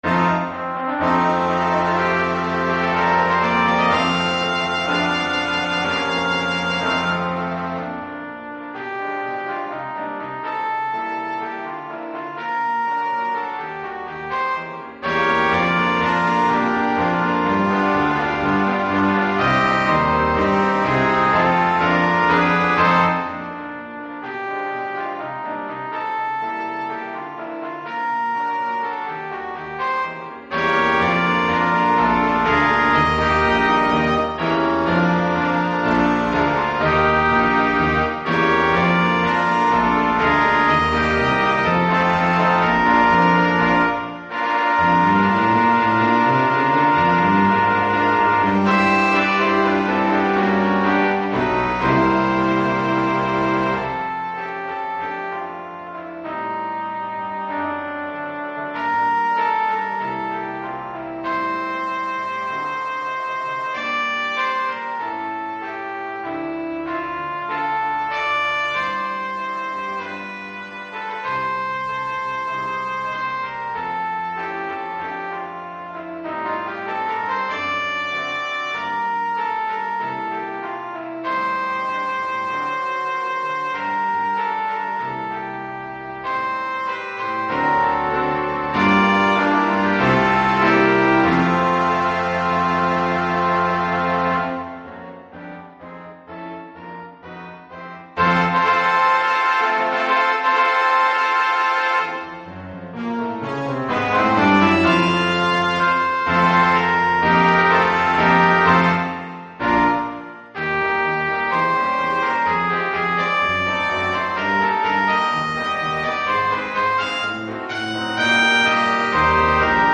Keyboard & Drums optional.
für variables Quintett Schwierigkeit
Besetzung: Ensemble gemischt PDF